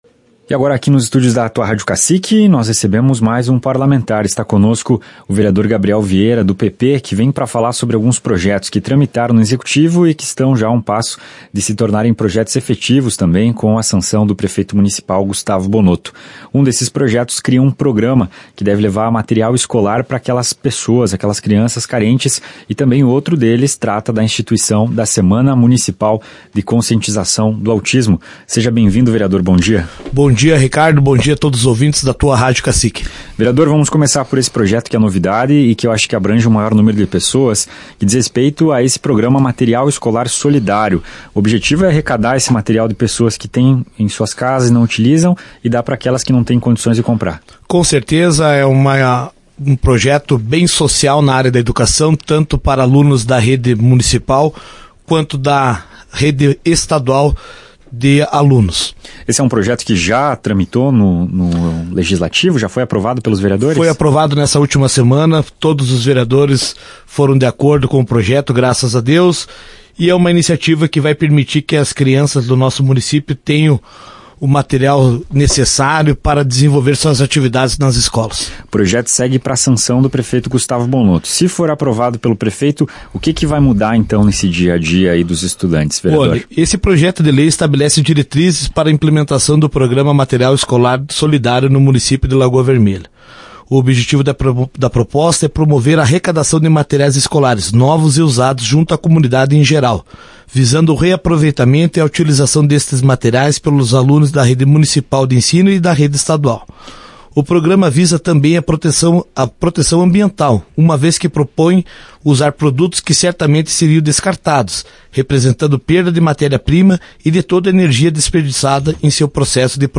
Ouça a entrevista com o vereador Gabriel Vieira.